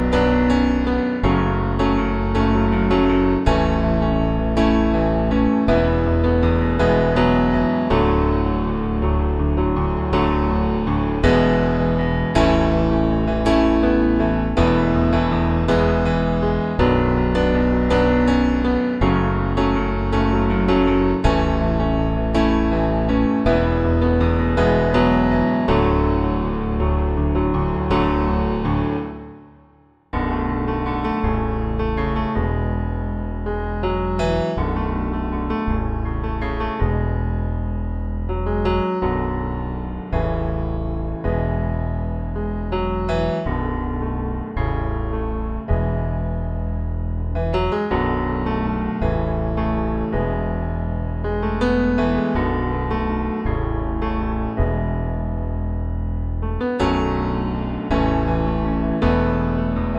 Five Semitones Down Pop (2010s) 4:04 Buy £1.50